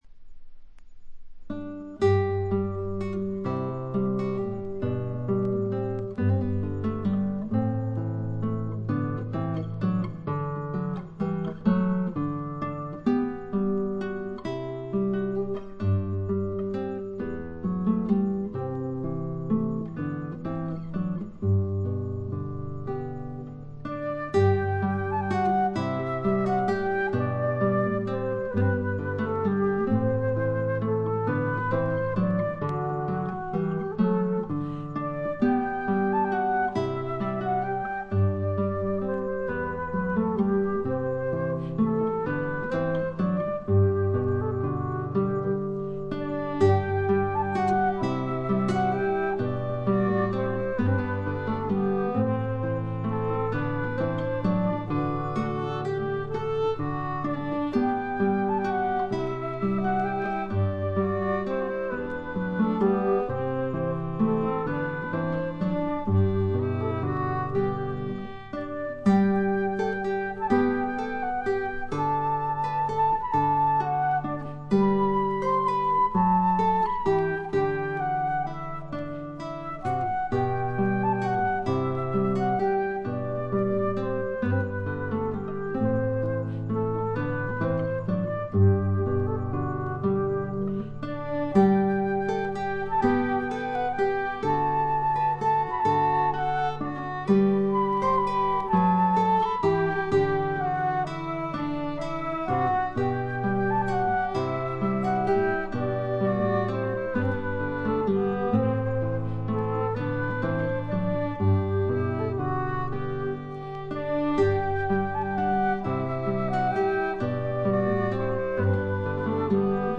オランダのトラッド・フォーク・グループ
試聴曲は現品からの取り込み音源です。
Violin, Flute, Mandolin, Whistle, Keyboards, Vocals